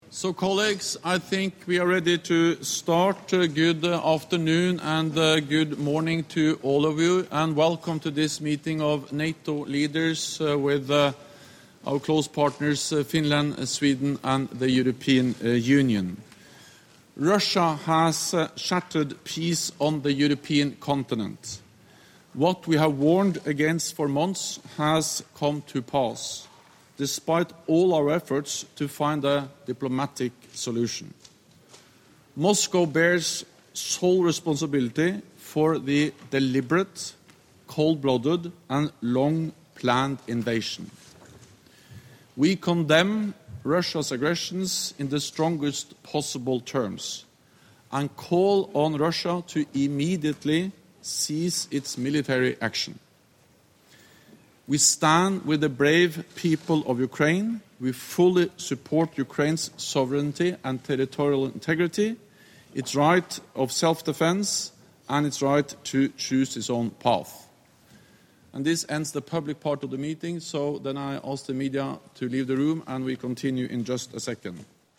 ENGLISH - Opening remarks by NATO Secretary General Jens Stoltenberg at the start of the extraordinary virtual summit of NATO Heads of State and Government